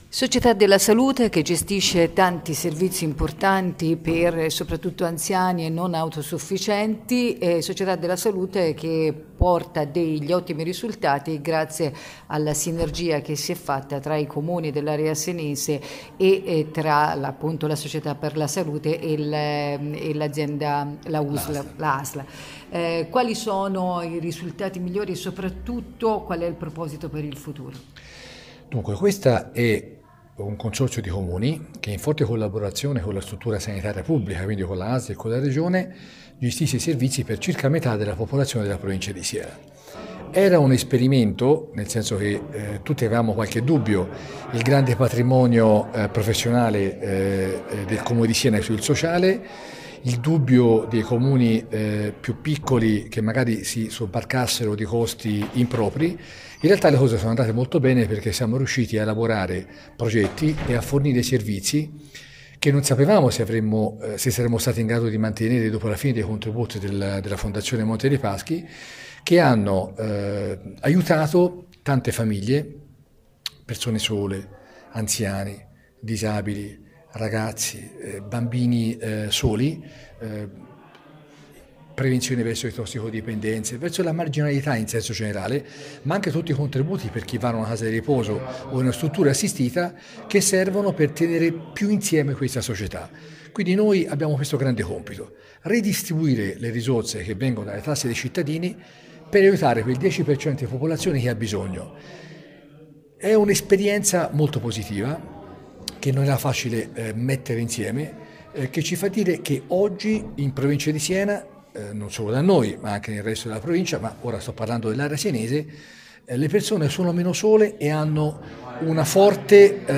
Il sindaco Bruno Valentini